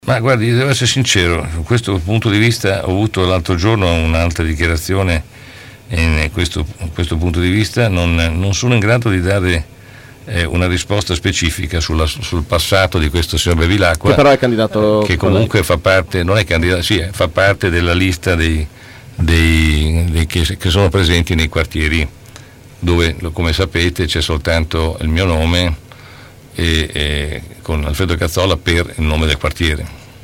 ai nostri microfoni, durante la trasmissione “Angolo B”.